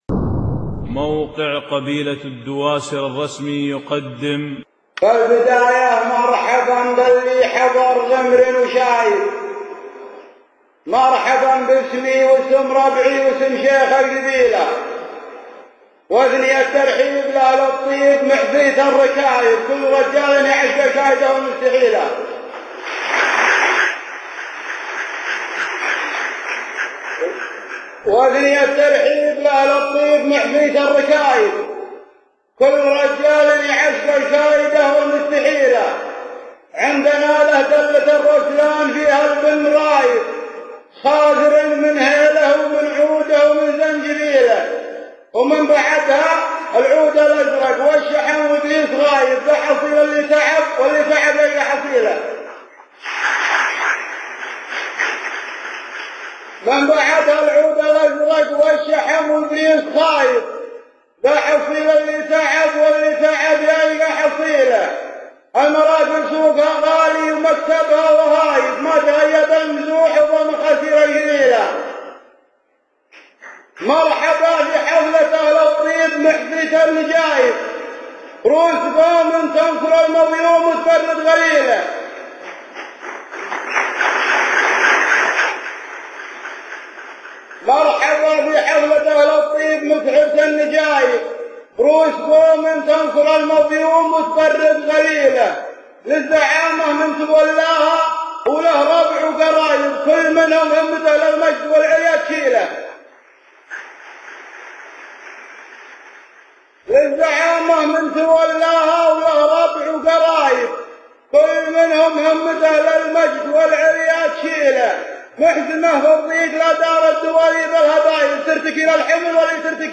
القصيدة الأولى : قصيدة ترحيبيّة .